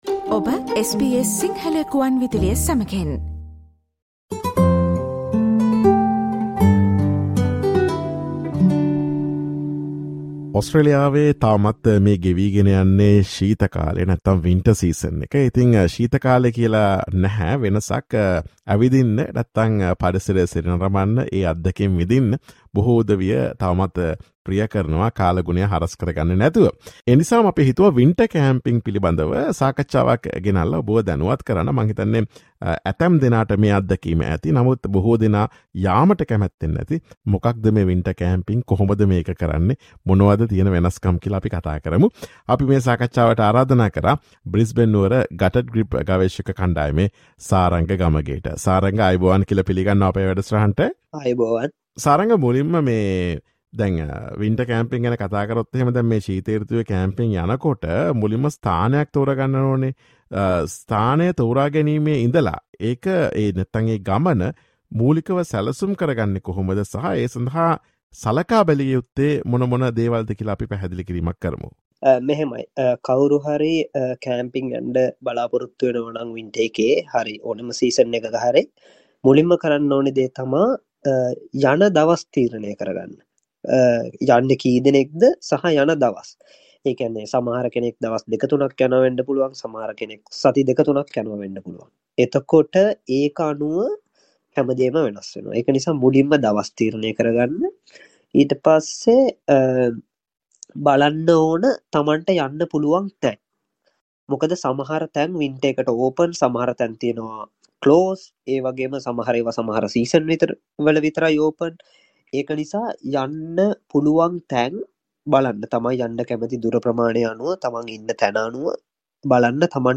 Listen to SBS Sinhala Radio's interview on how to prepare for camping in Australia during winter.